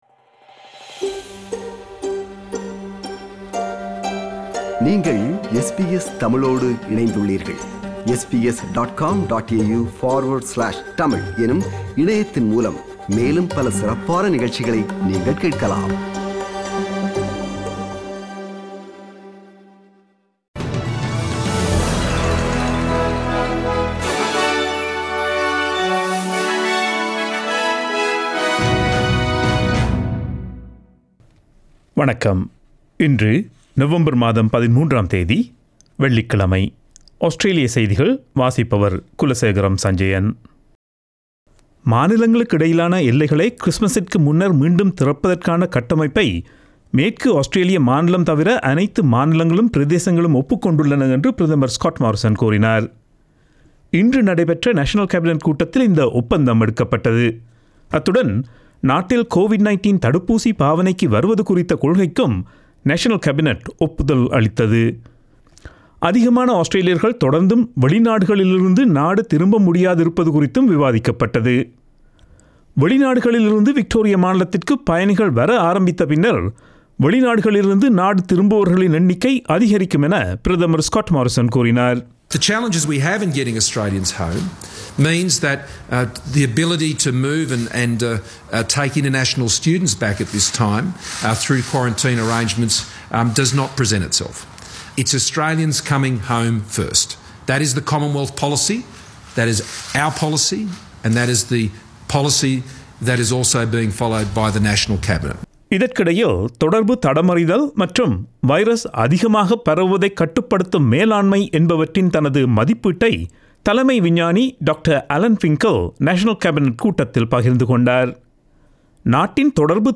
Australian news bulletin for Friday 13 November 2020.